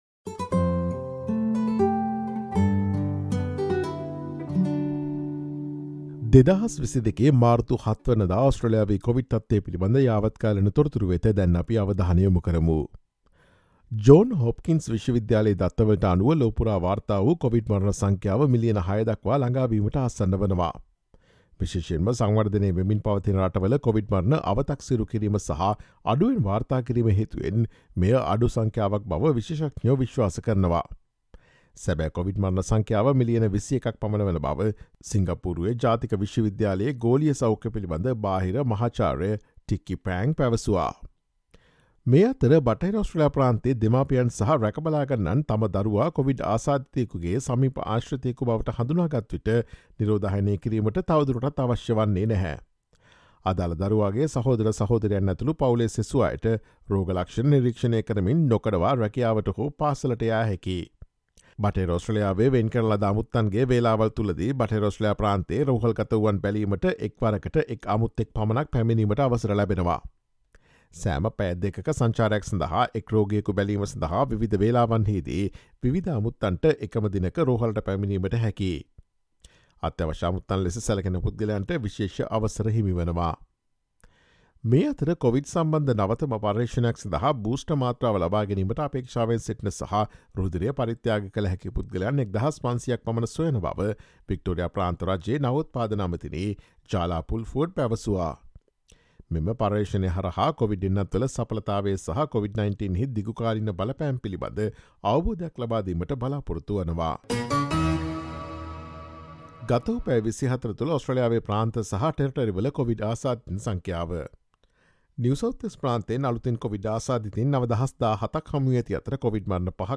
SBS සිංහල ගුවන් විදුලිය ගෙන එන 2022 මාර්තු 07 වනදා ඔස්ට්‍රේලියාවේ කොවිඩ් තත්ත්වය පිළිබඳ යාවත්කාලීන තොරතුරු වලට සවන් දෙන්න.